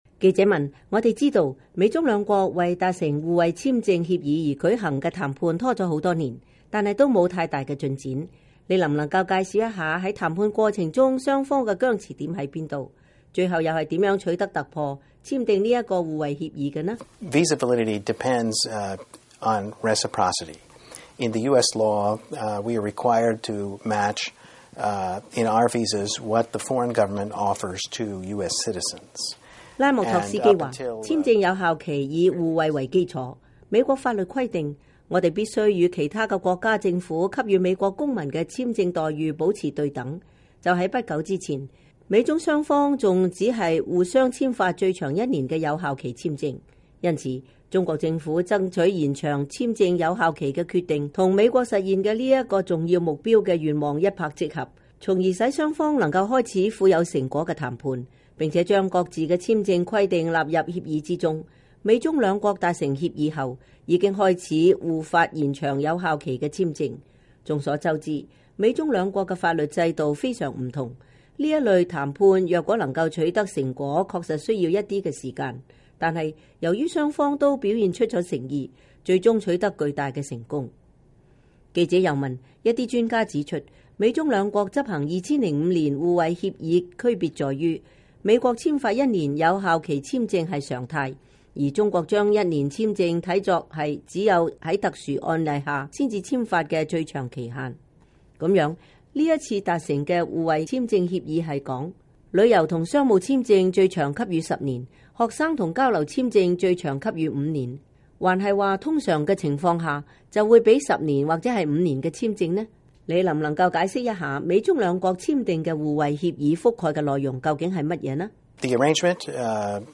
專訪美國高官 談美中互惠簽證協議